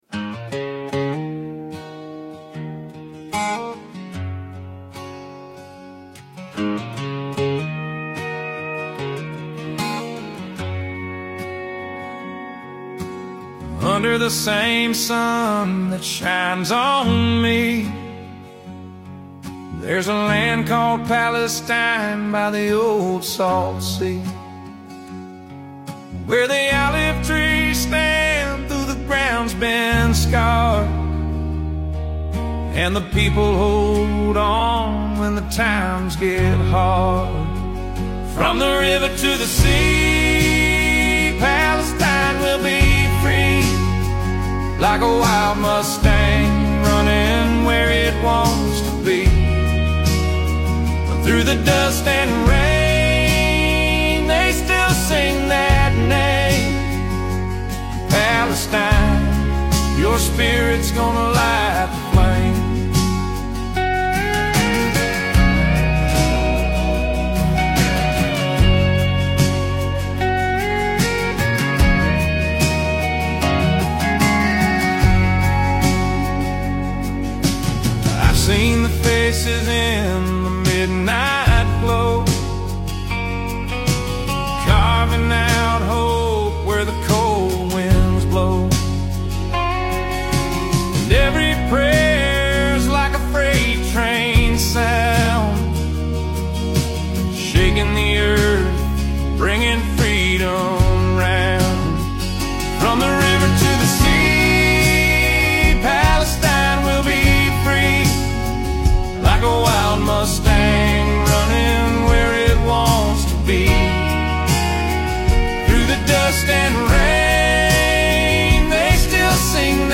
country song